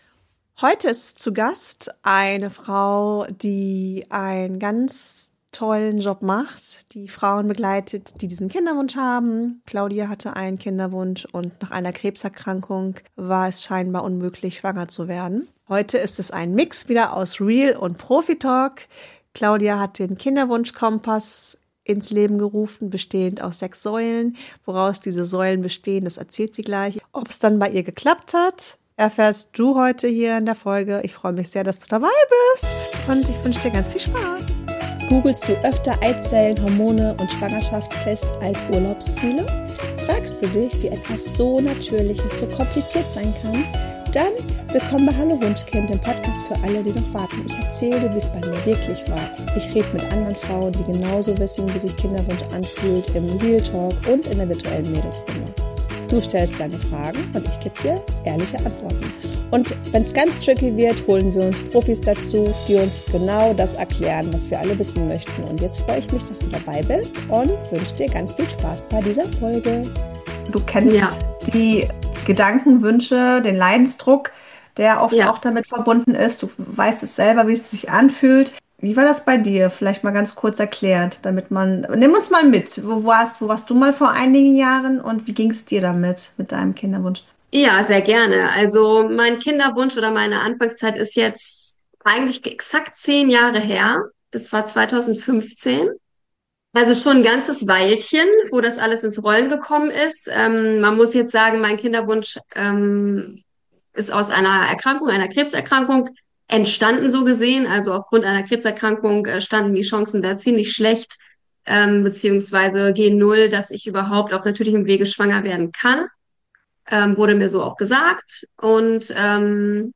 Ein Gespräch über körperliche Grenzen, emotionale Prozesse und Entscheidungen, die alles verändern können.